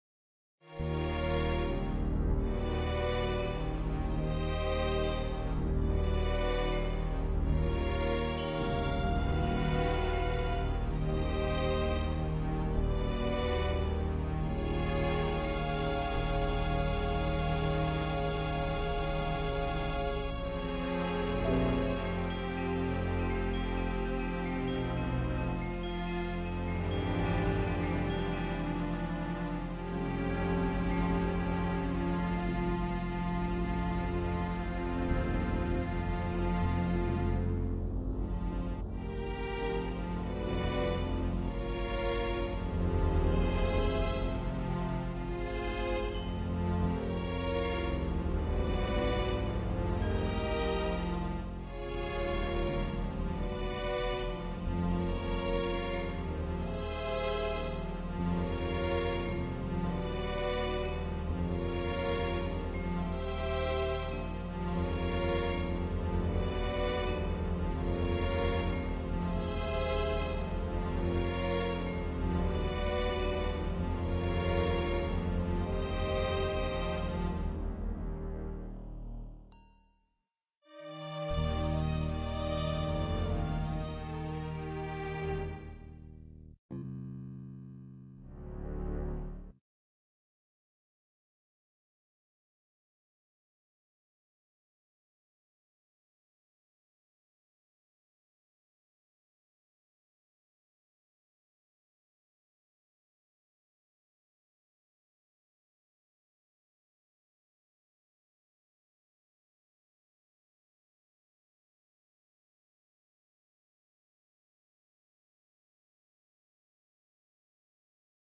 Dark strings